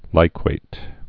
(līkwāt)